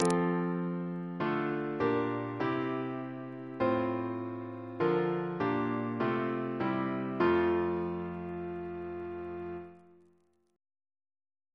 Single chant in F Composer: Frank Henry Champneys (1848-1930) Reference psalters: ACB: 216